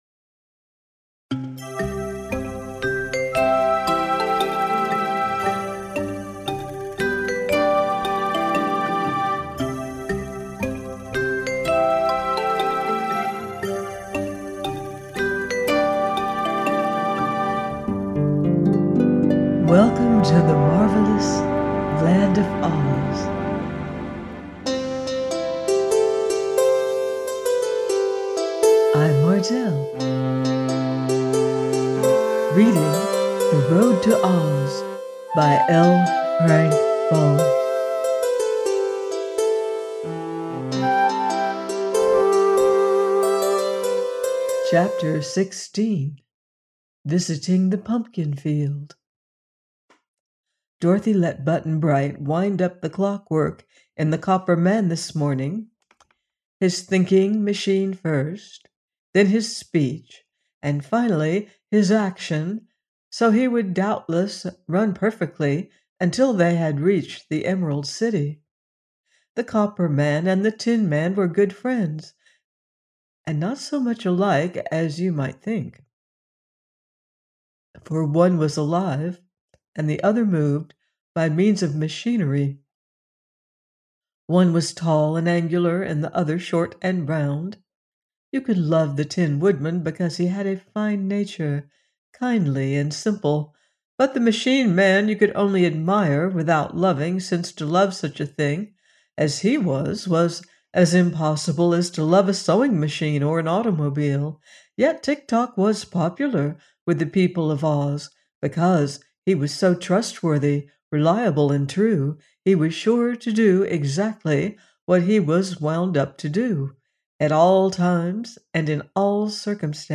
The Road To OZ – by L. Frank Baum - audiobook